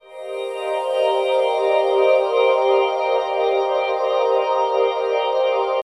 Index of /90_sSampleCDs/Zero-G - Total Drum Bass/Instruments - 1/track10 (Pads)
01 Shifted Glass Cmin.wav